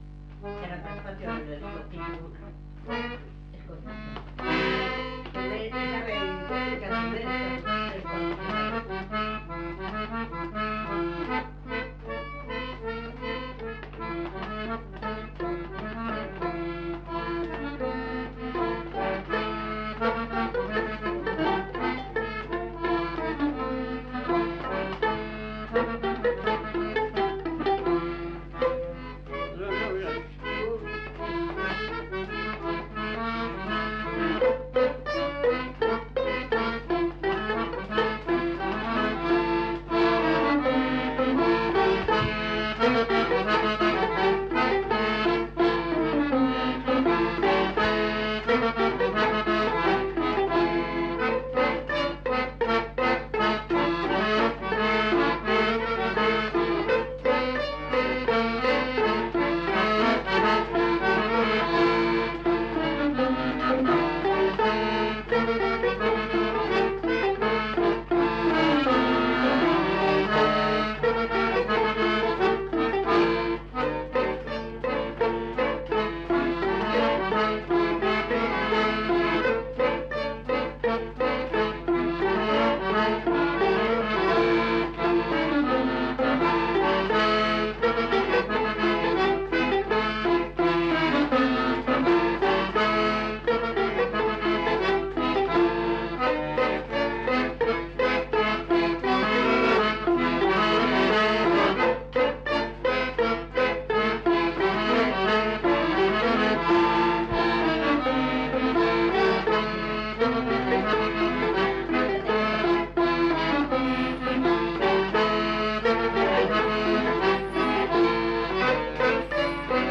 Répertoire de danses des Petites-Landes interprété au violon et à l'accordéon chromatique